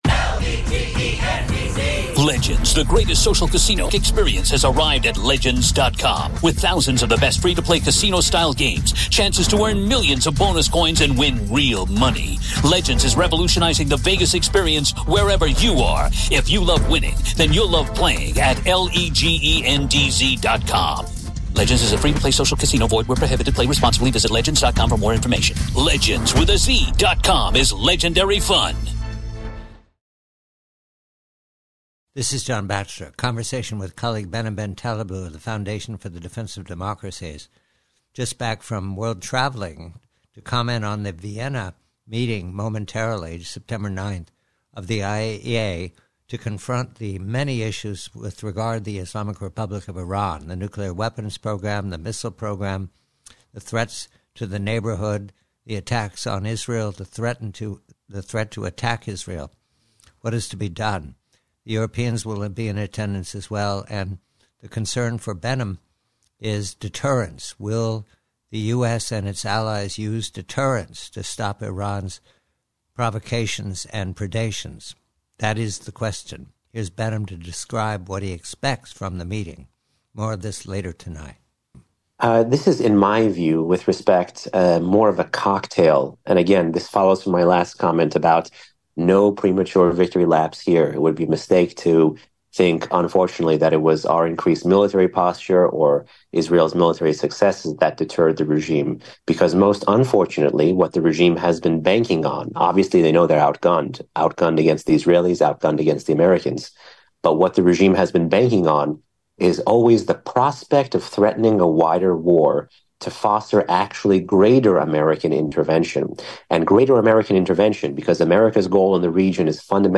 PREVIEW: IRAN: IAEA: US NAVY: Conversation